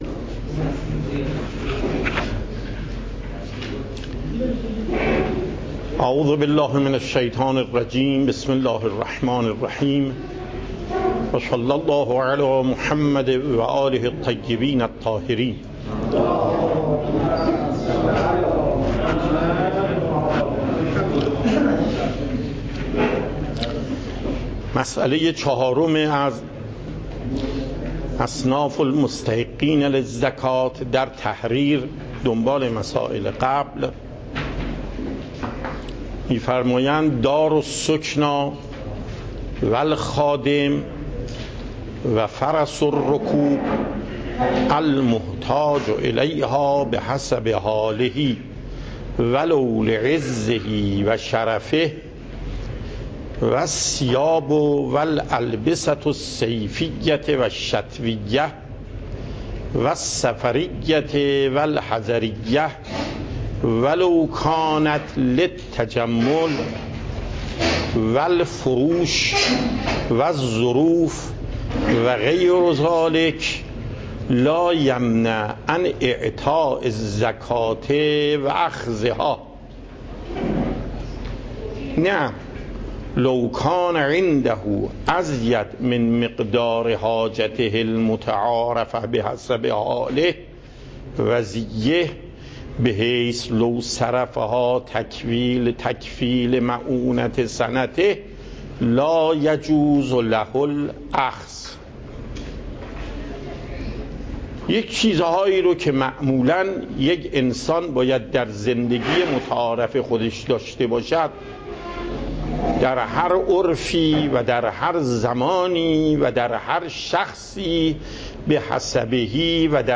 صوت درس
درس فقه آیت الله محقق داماد